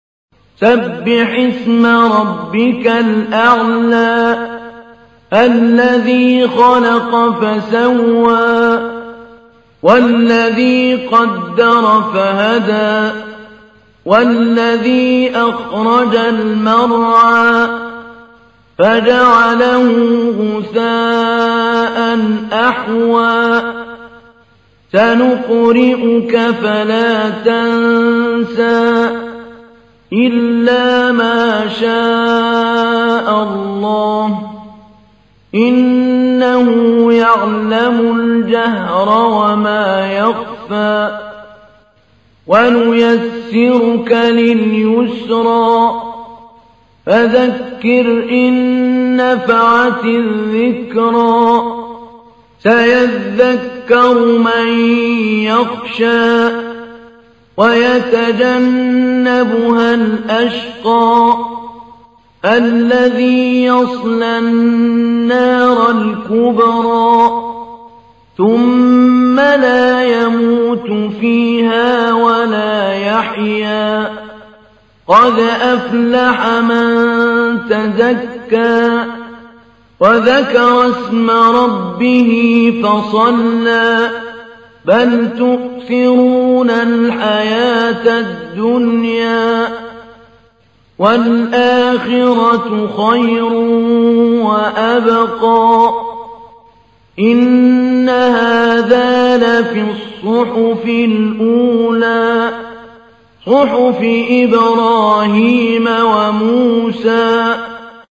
تحميل : 87. سورة الأعلى / القارئ محمود علي البنا / القرآن الكريم / موقع يا حسين